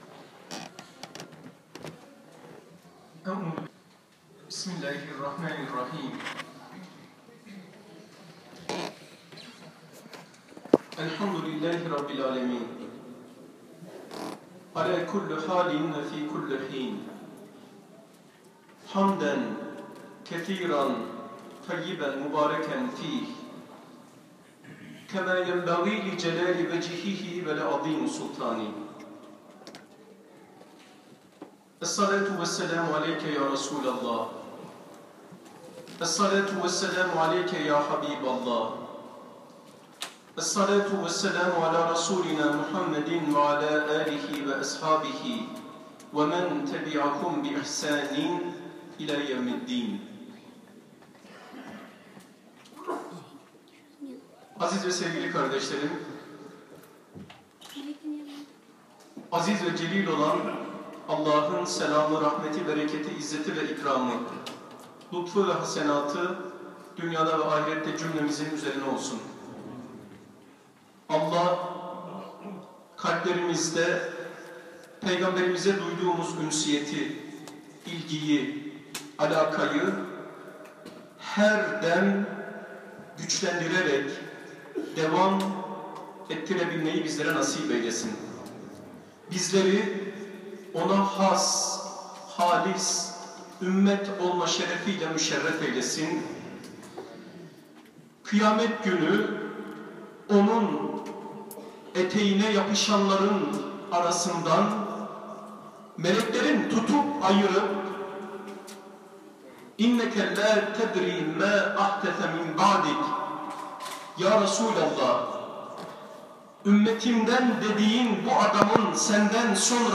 29 Kasım 2017 Çarşamba günü (11 Rebiulevvel 1439) akşamı, yatsı namazına müteakip Antalya Ahlak Kültür Çevre ve Eğitim Derneğimiz tarafından organize edilen Mevlid-i Nebi 1439 programı, Hacı Emin Aksu Camiinde, zikirler, dualar, hatimler eşliğinde güzel bir program ve yağun katılım ile gerçekleşti.